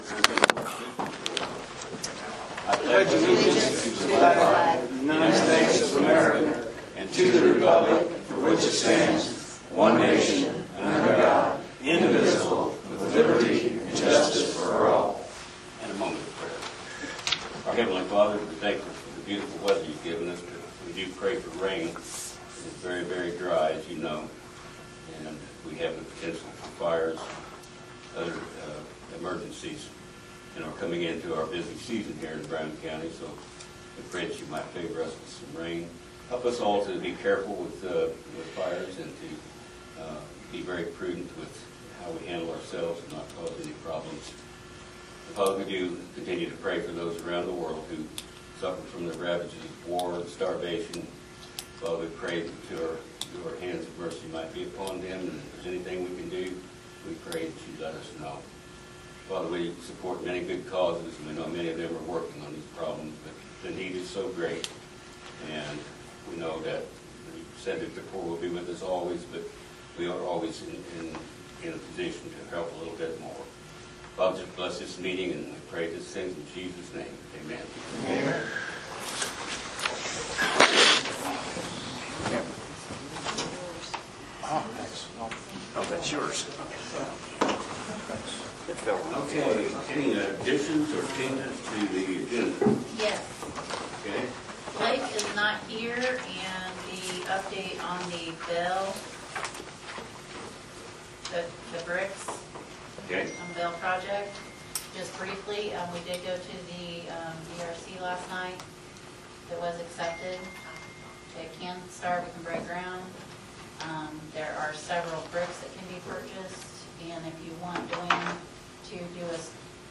Commissioner Meeting Notes, Sep 18, 2024